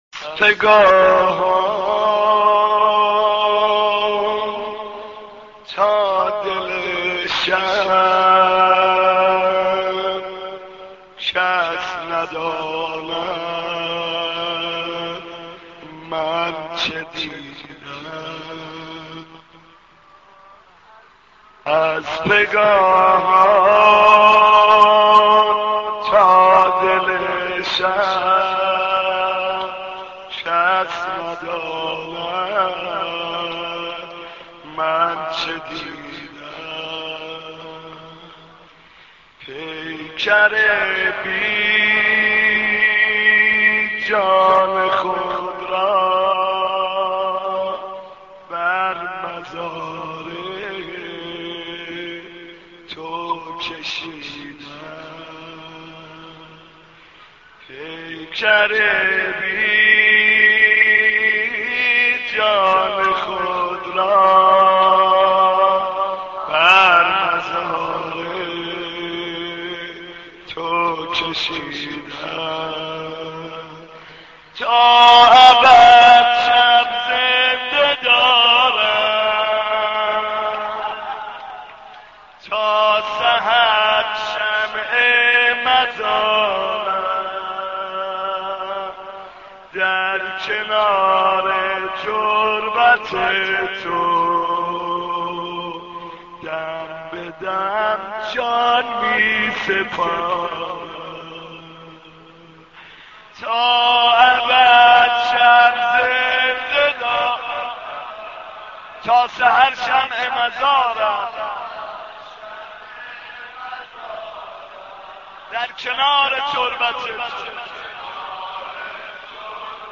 دانلود مداحی دم به دم جان می سپارم - دانلود ریمیکس و آهنگ جدید
مرثیه خوانی